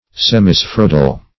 Semispheroidal \Sem`i*sphe*roid"al\
semispheroidal.mp3